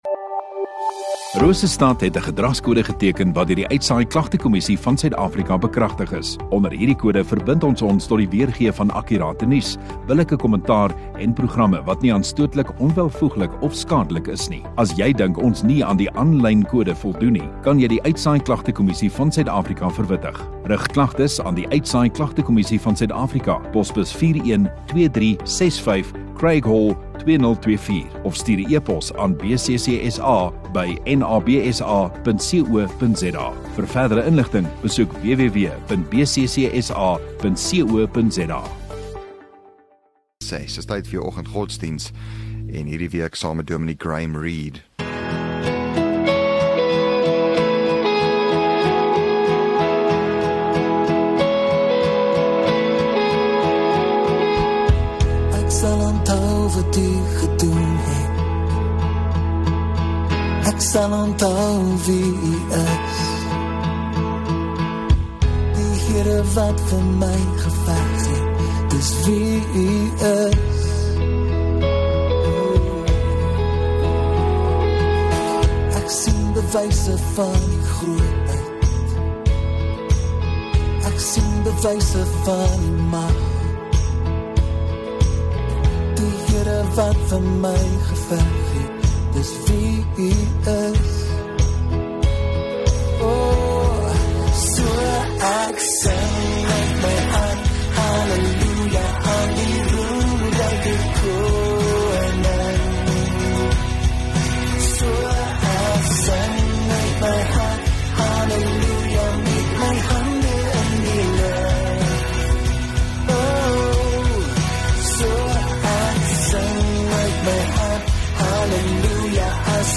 17 Apr Donderdag Oggenddiens